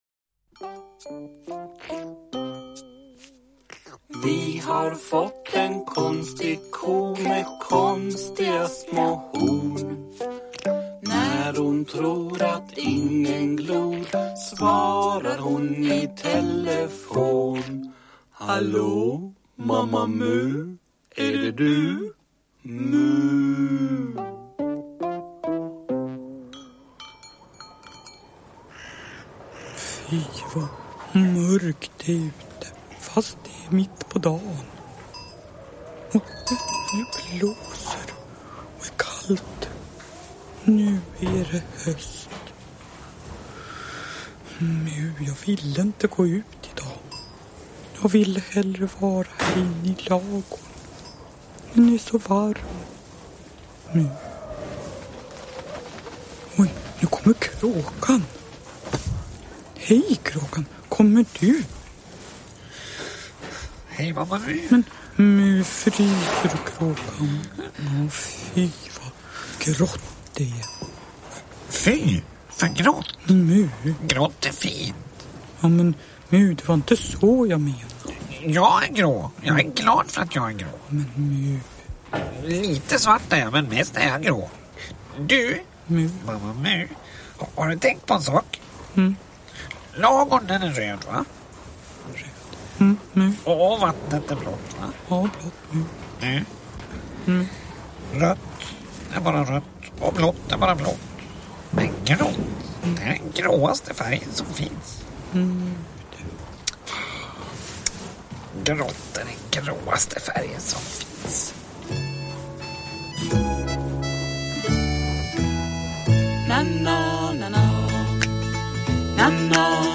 Uppläsning med dramatisering.
Uppläsare: Jujja Wieslander